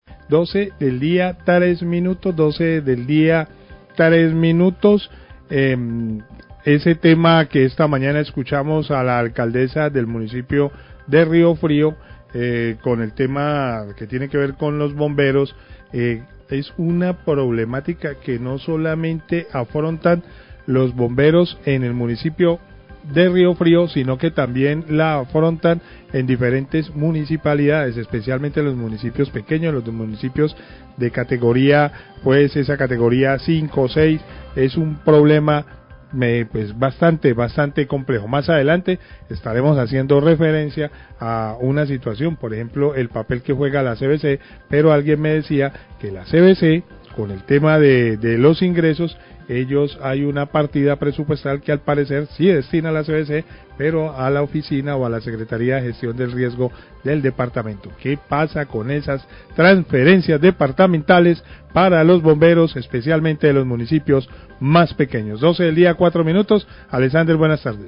Periodista comenta dificil situación financiera de bomberos y papel de la CVC,
Radio